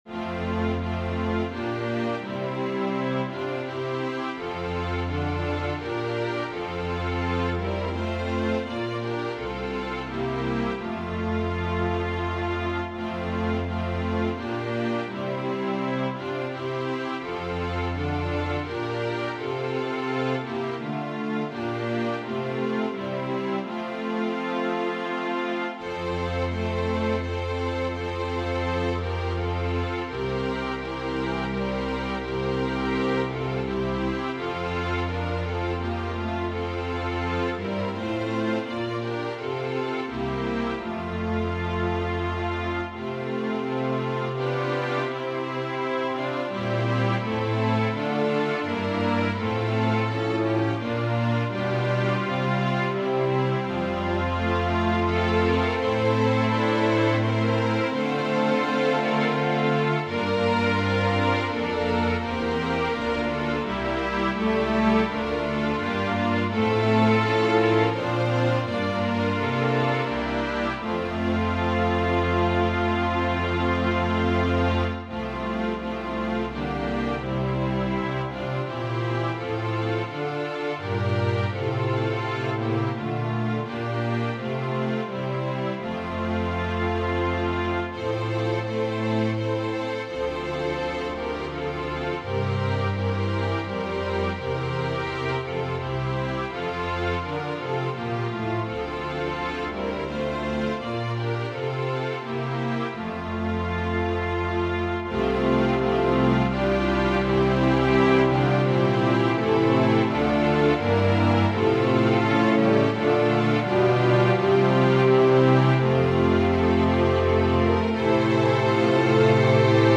Voicing/Instrumentation: Organ/Organ Accompaniment We also have other 7 arrangements of " My Country, 'Tis Of Thee ".